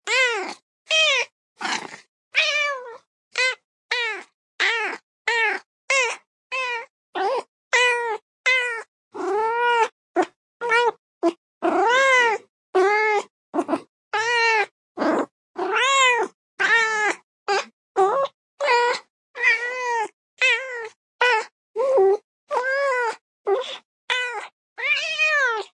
Annoyed Cat Meows Grunt Vowels Sound Effect Download: Instant Soundboard Button